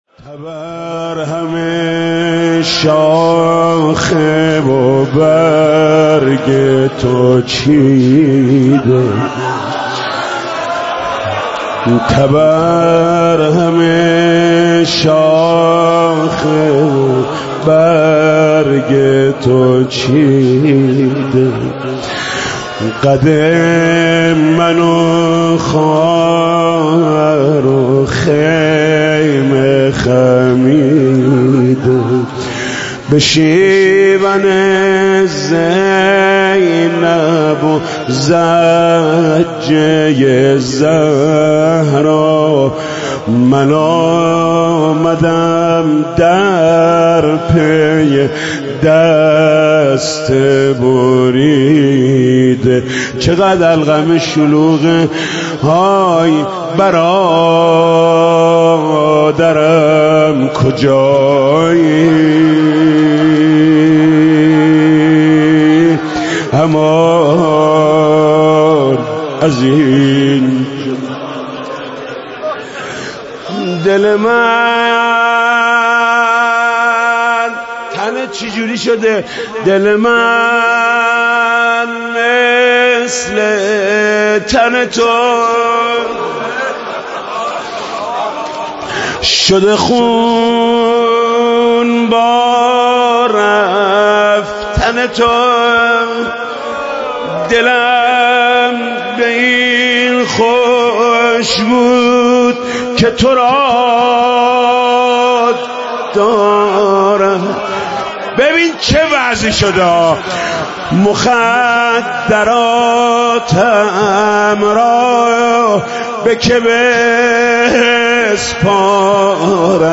مداحی جدید حاج محمود کریمی وفات حضرت ام البنین (س) دوشنبه 29 بهمن 1397 هیات رایة العباس (ع) تهران